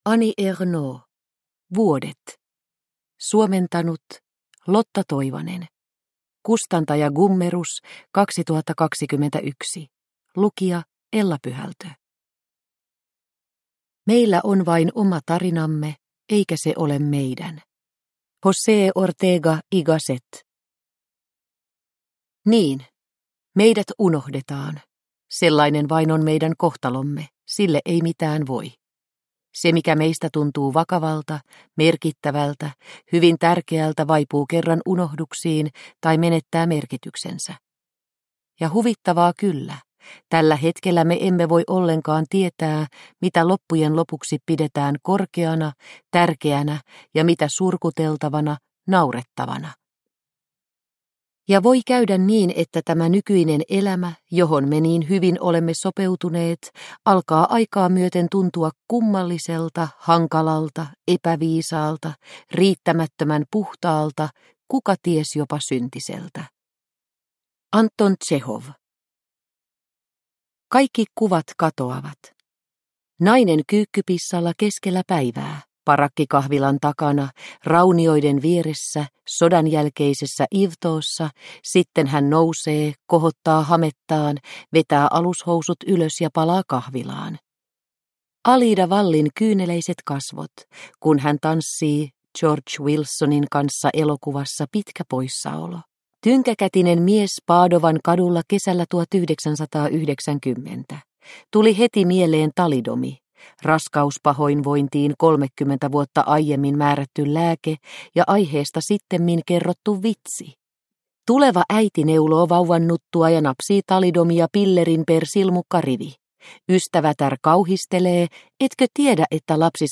Vuodet – Ljudbok – Laddas ner